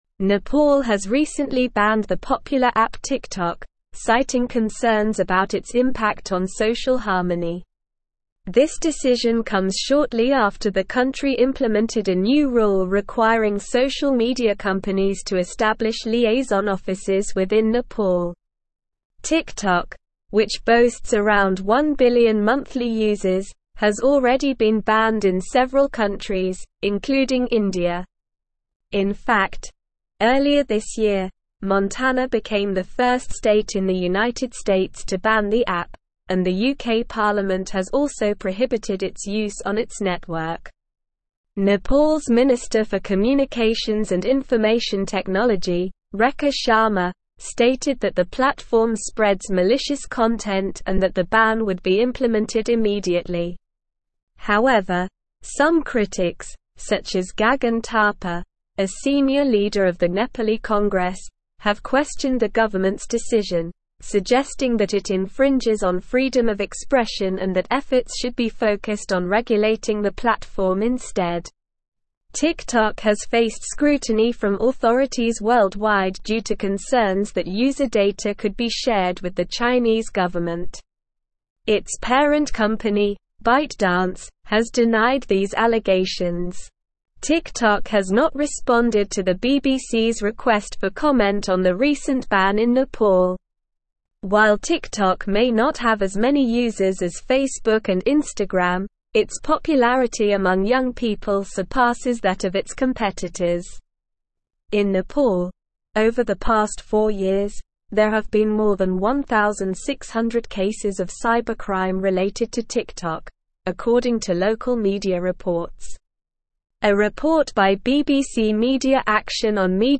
Slow
English-Newsroom-Advanced-SLOW-Reading-Nepal-Bans-TikTok-Over-Social-Harmony-Concerns.mp3